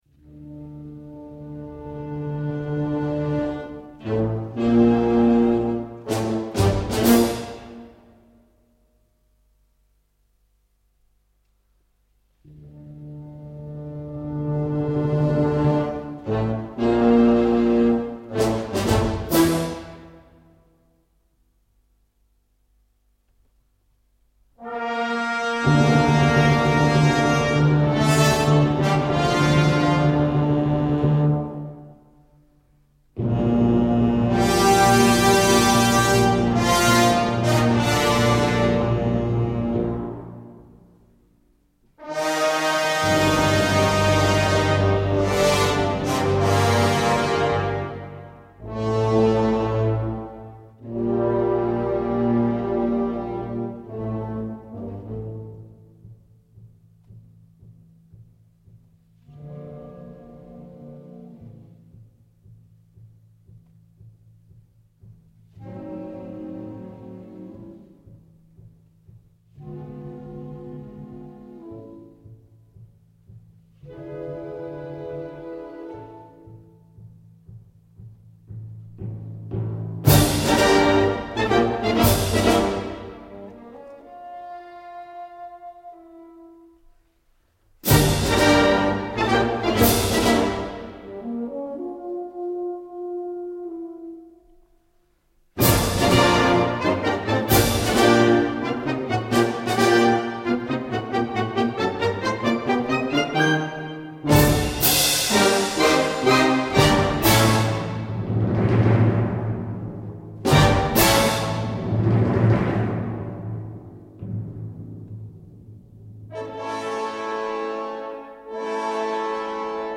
Gattung: Dokumentation in 4 Sätzen
Besetzung: Blasorchester